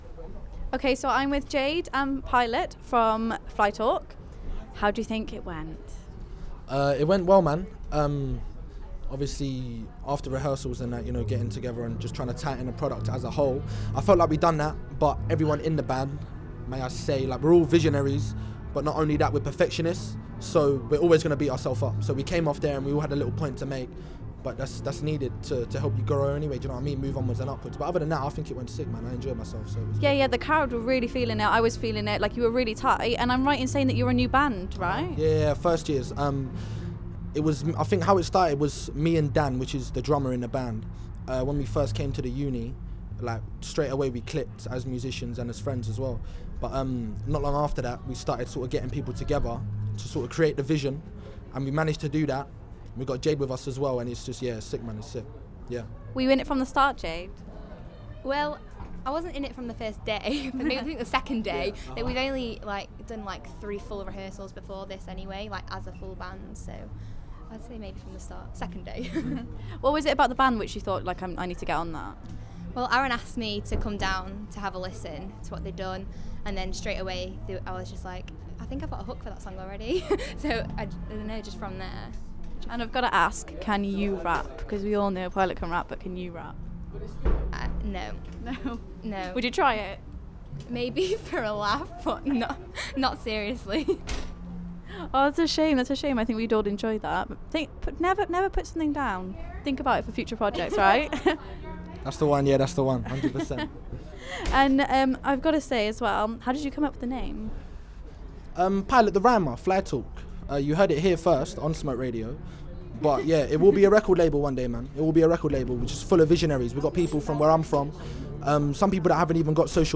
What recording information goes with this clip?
iSessions Westminster: Charity Battle of the Bands#iSessionsWestminster Smoke Radio went along to this years iSessions and caught up with all the bands and performers after their set.